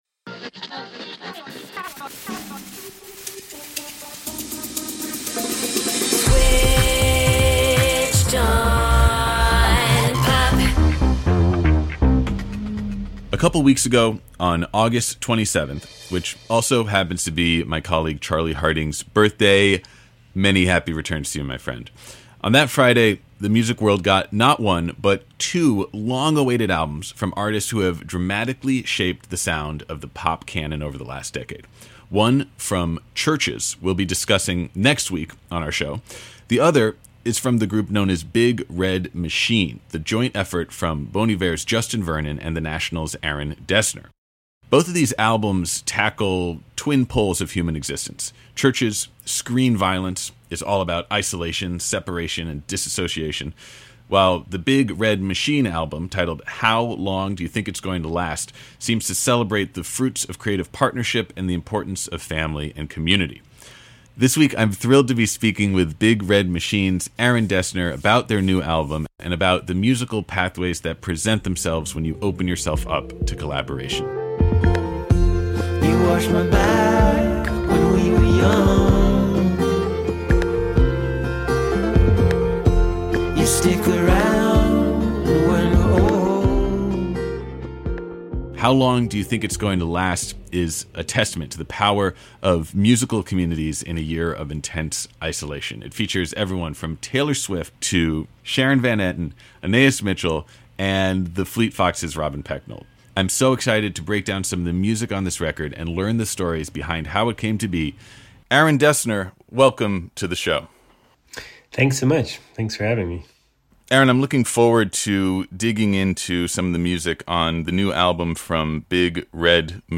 The Big Red Machine album, titled How Long Do You Think it's Going to Last, celebrates the fruits of creative partnership and the importance of family and community. At least, that’s what we took from our conversation with Dessner.